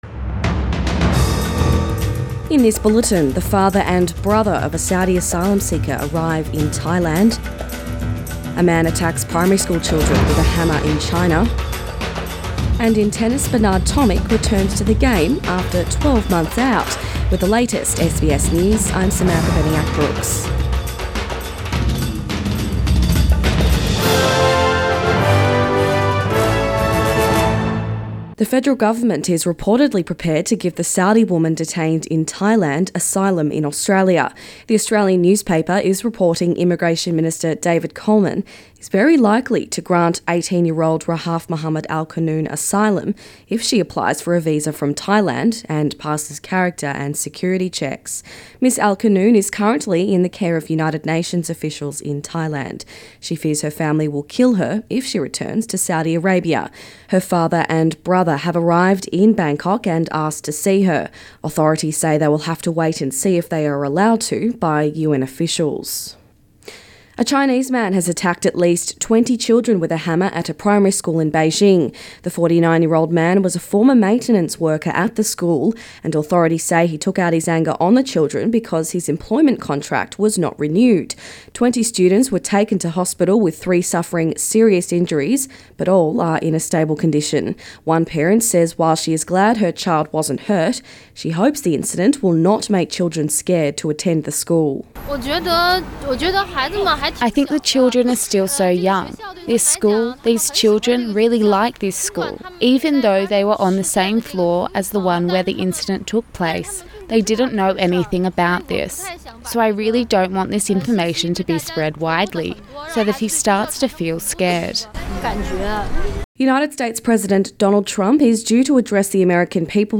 AM bulletin 9 January